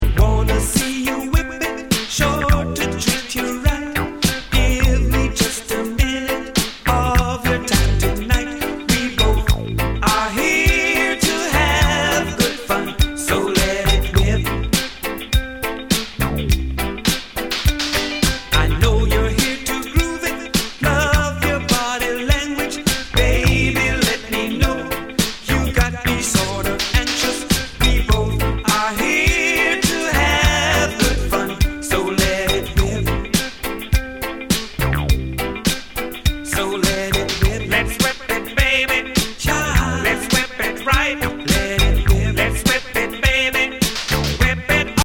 Lovers reggae diva
Contains R&B flavor and the most mellow reggae cover!